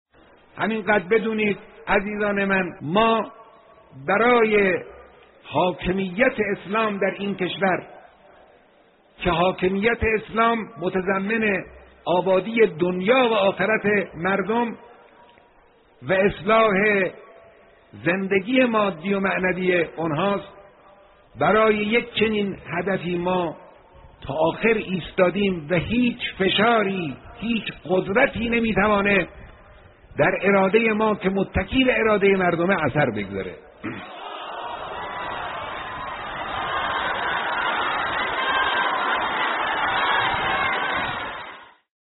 گزیده بیانات رهبر انقلاب در دیدار عمومی/حاکمیت اسلام